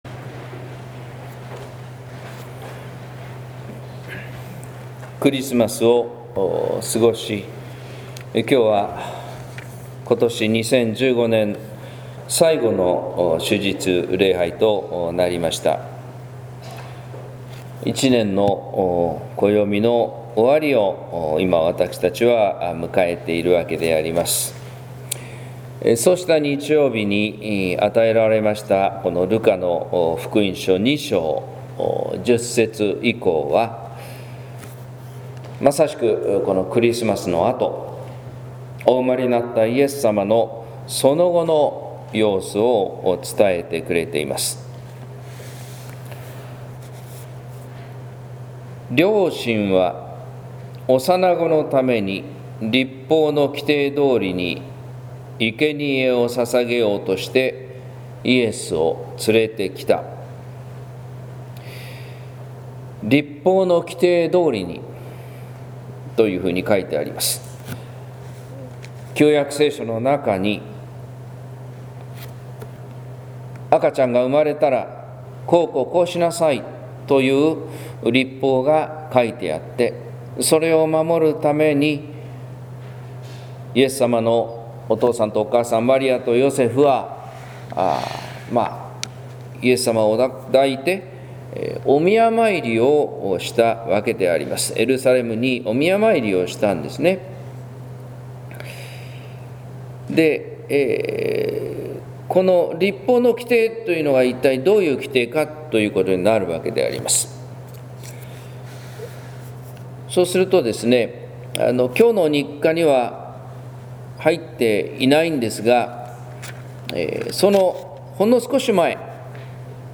説教「シメオンと第九を歌う」（音声版）